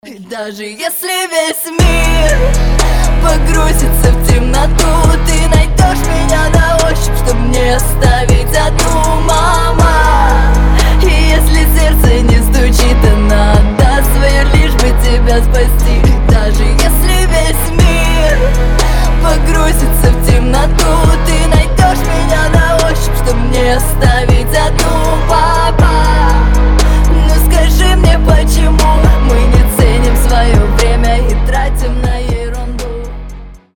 красивые
душевные